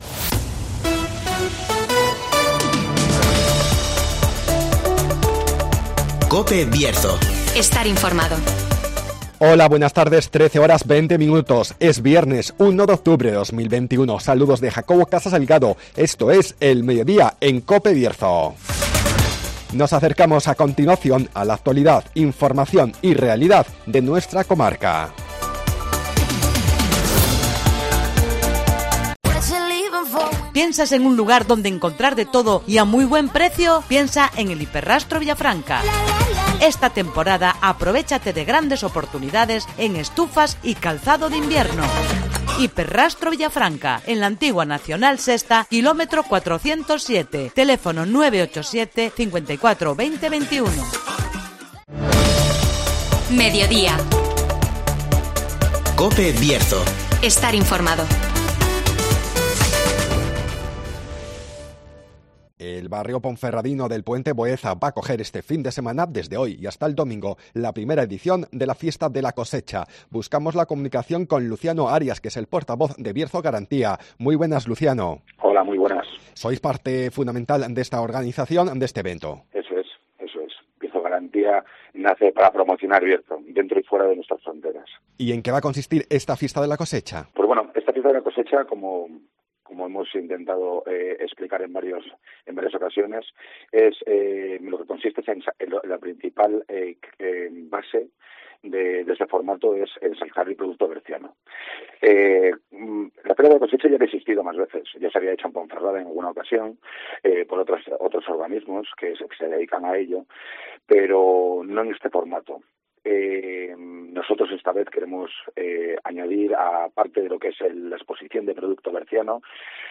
El Puente Boeza acogerá la primera Fiesta de la Cosecha. Entrevista